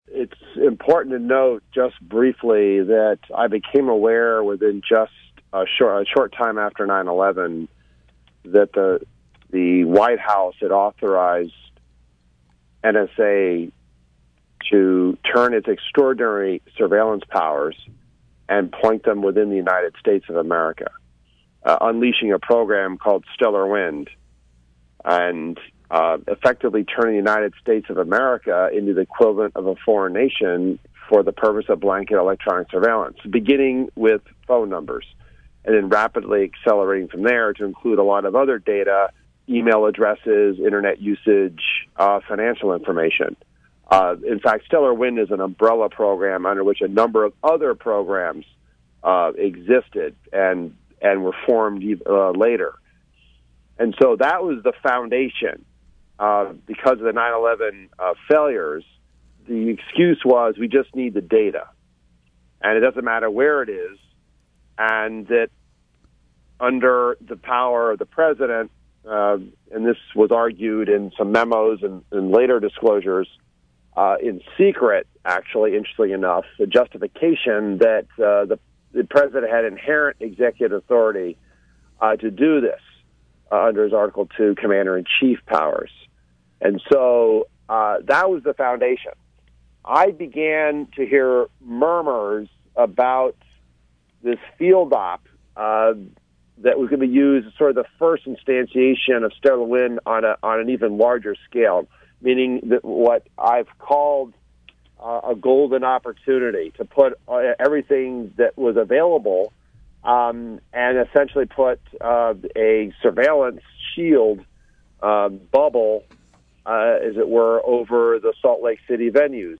In-Depth Interview: Tom Drake and Rocky Anderson Reveal Dragnet Surveillance at Salt Lake Olympics
There’s a lot more in this conversation with two stalwart defenders of the Fourth Amendment.